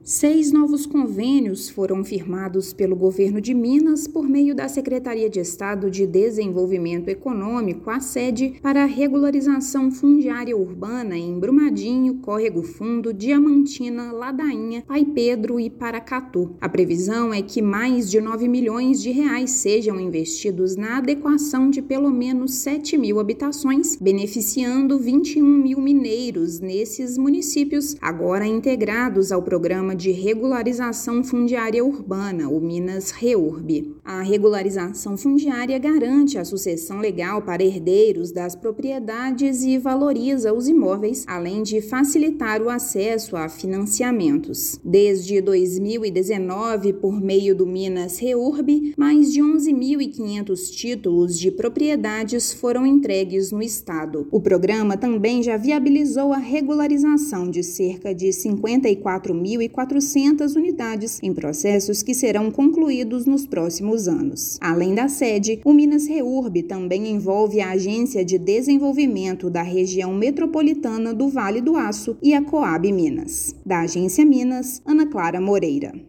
Ação da Secretaria de Estado de Desenvolvimento Econômico envolve acordos com os municípios de Brumadinho, Córrego Fundo, Diamantina, Ladainha, Pai Pedro e Paracatu. Ouça matéria de rádio.